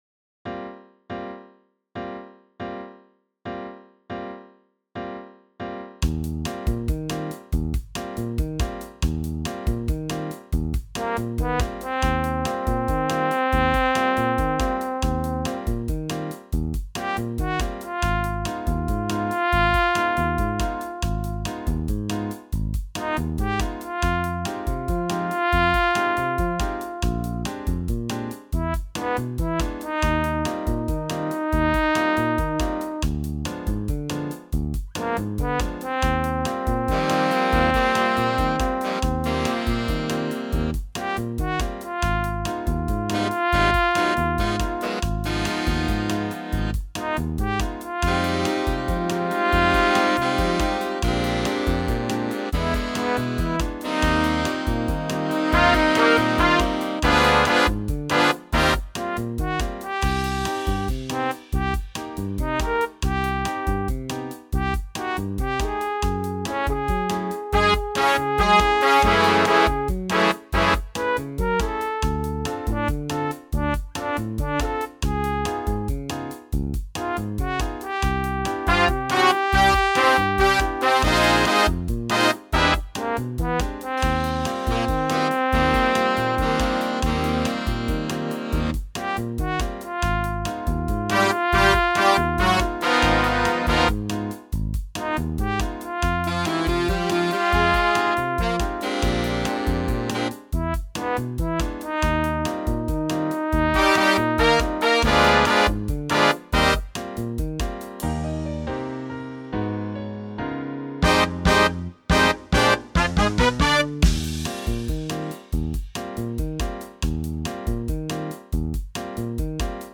Bossa nova in 7/4. Trombone feature.